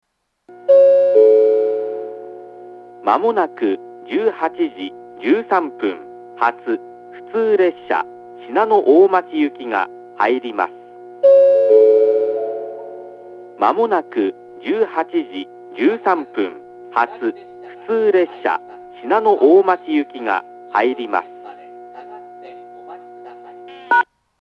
１番線接近予告放送 18:13発普通信濃大町行の放送です。